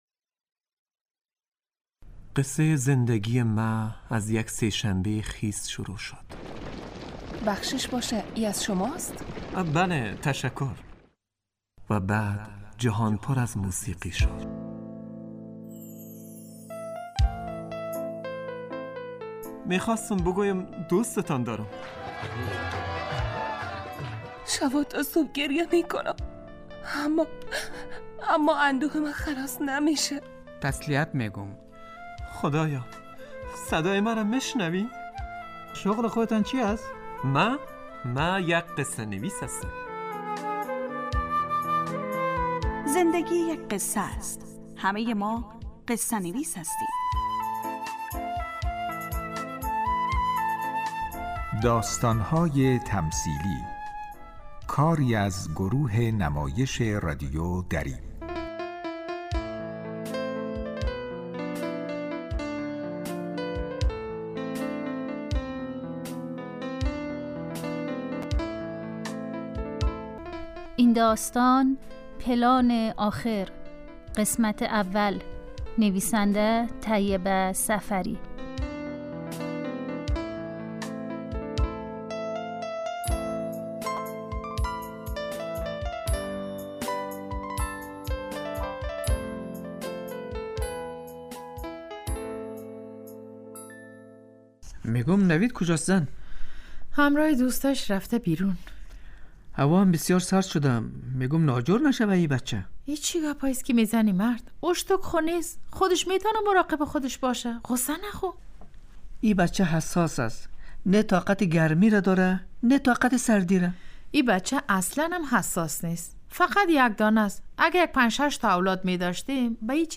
داستانهای تمثیلی نمایش 15 دقیقه ای هستند